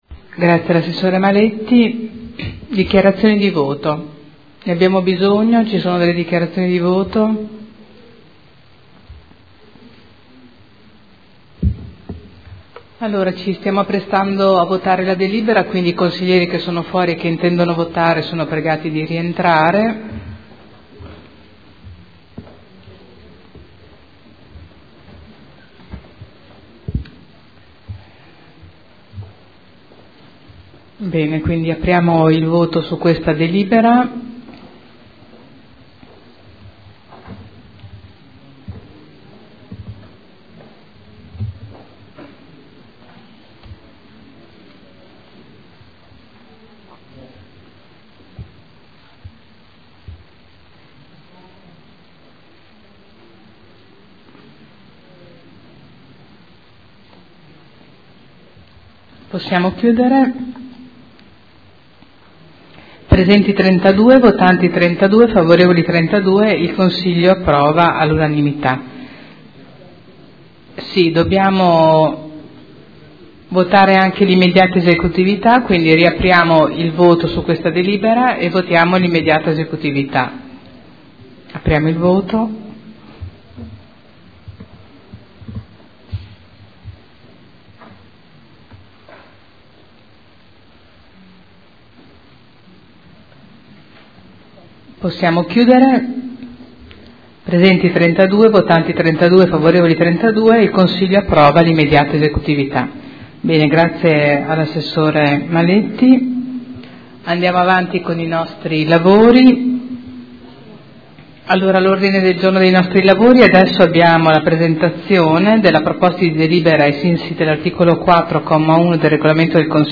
Presidente — Sito Audio Consiglio Comunale
Seduta del 05/11/2012. Mette ai voti proposta di deliberazione: Linee di indirizzo per l’affidamento del servizio di trasporto disabili alle attività diurne, socio-occupazionali e del tempo libero – Periodo dal 1.3.2013 al 28.2.2015 e immediata esecutività